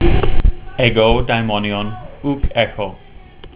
You can click on the verse to hear me read it.